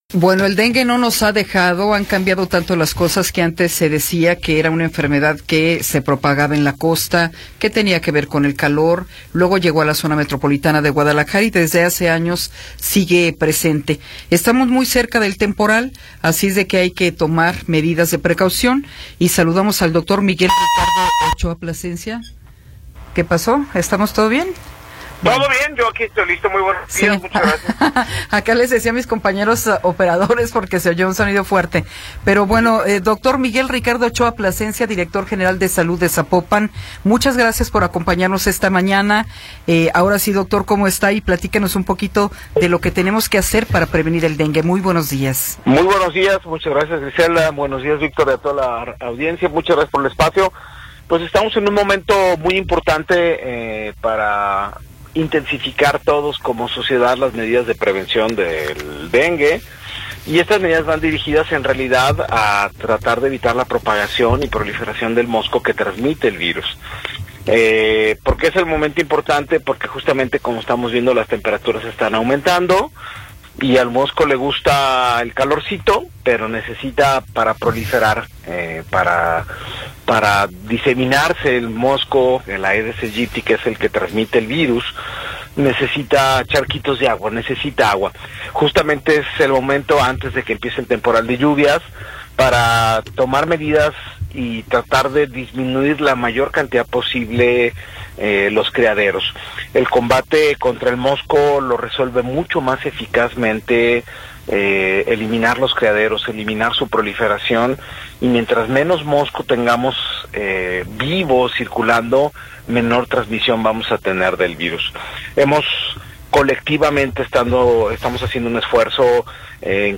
Entrevista con Miguel Ricardo Ochoa Plascencia